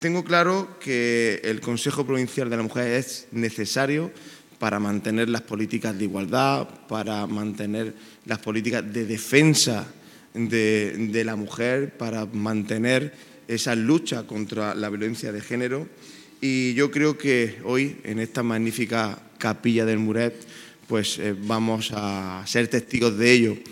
El plenario de este órgano de la Institución Provincial se ha celebrado en el Museo del Realismo Español Contemporáneo, MUREC, con una visita guiada a su colección permanente y exposiciones temporales
Durante su intervención, el presidente ha destacado la idoneidad del escenario, calificándolo como la “catedral del realismo”.